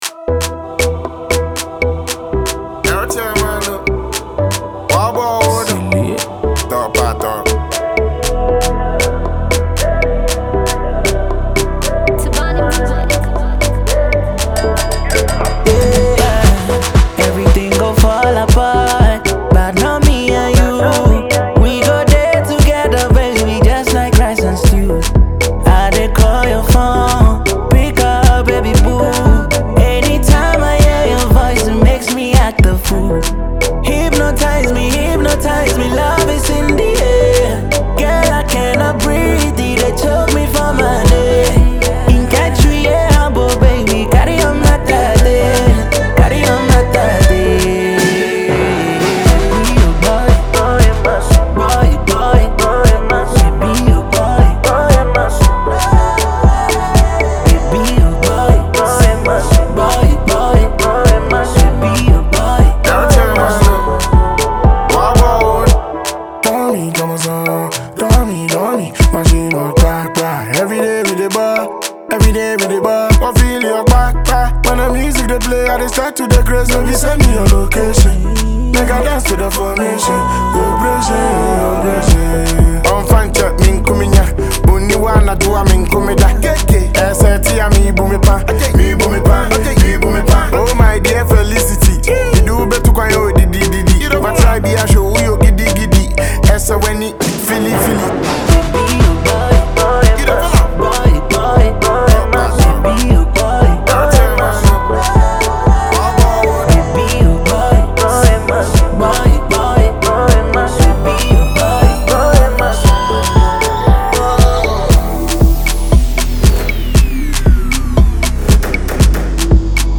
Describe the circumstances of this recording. studio tune